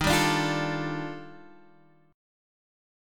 D#M#11 Chord